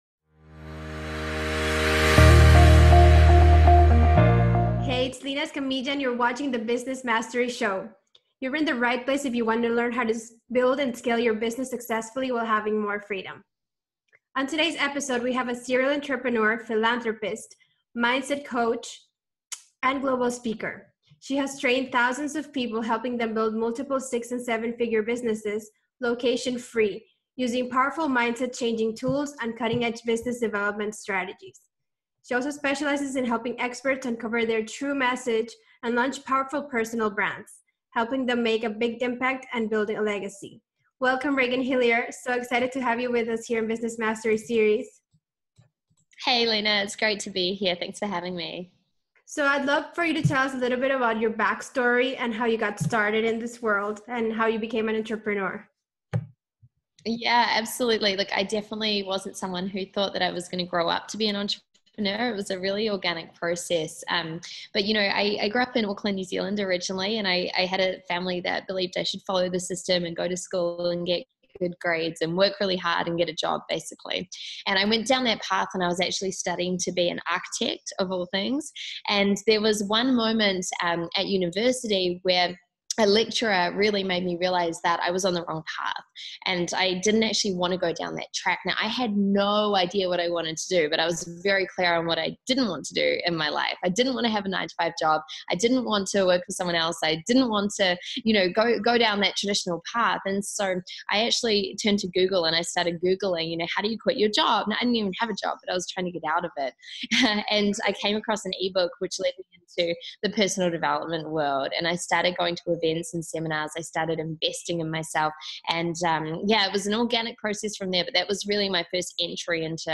What you’ll learn in this interview